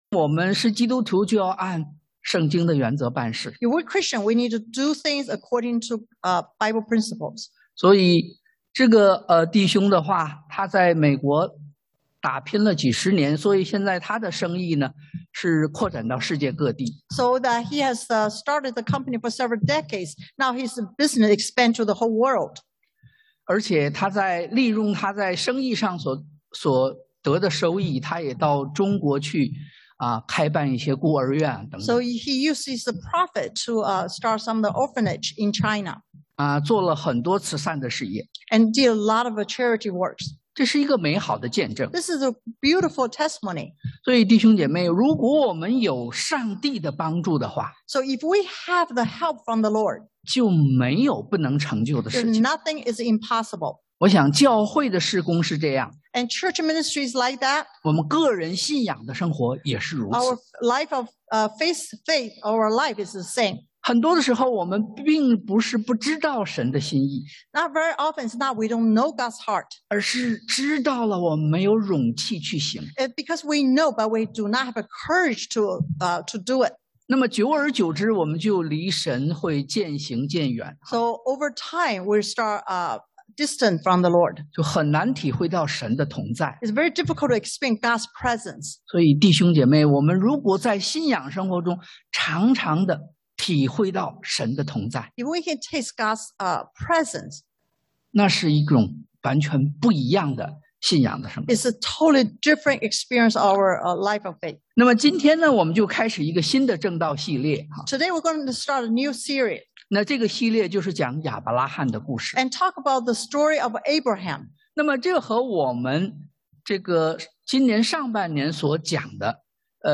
創 Gen 11:27-12:9 Service Type: Sunday AM The Beginning of a Faith Journey 信心出發 經文Passage